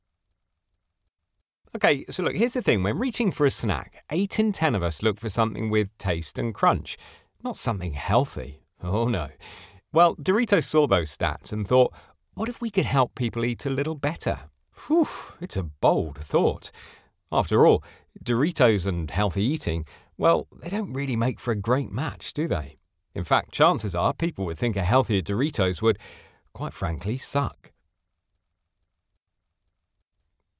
Commercial
Male
30s, 40s, 50s
British English (Native)
Approachable, Assured, Authoritative, Bright, Conversational, Corporate, Engaging, Friendly, Natural, Posh, Reassuring, Warm
RP (native)
Microphone: Neumann TLM103 & Sennheiser MKH 416